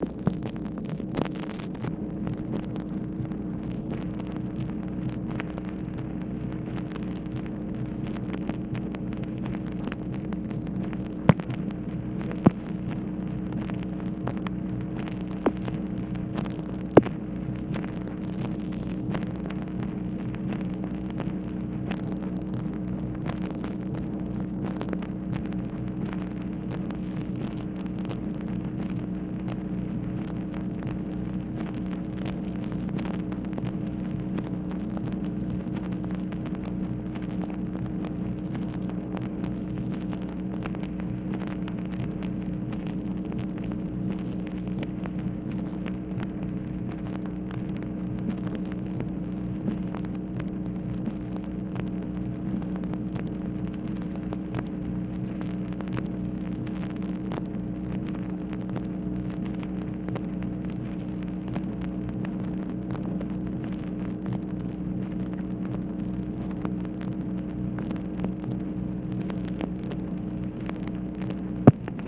MACHINE NOISE, November 4, 1964
Secret White House Tapes